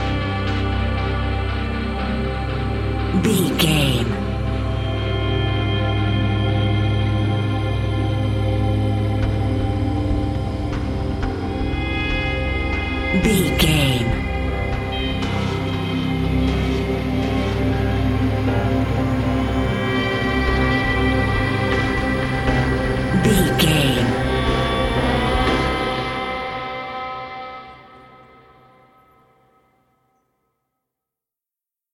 Thriller
Aeolian/Minor
synthesiser
percussion